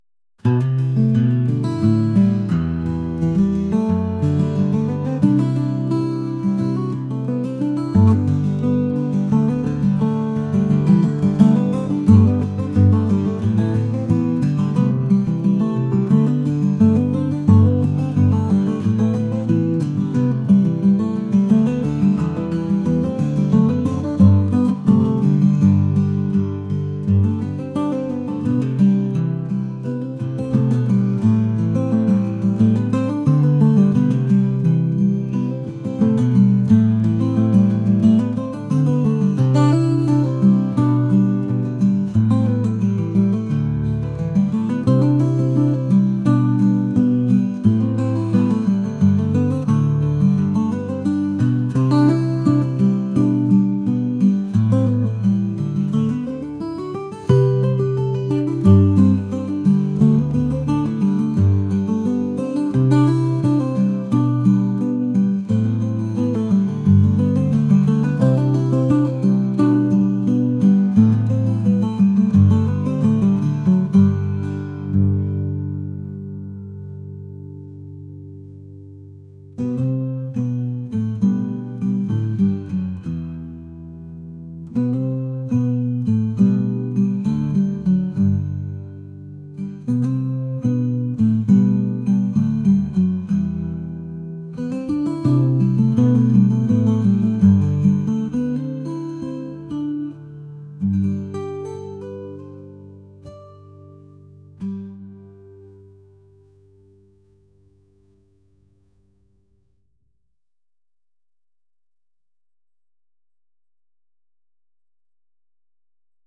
acoustic | folk | indie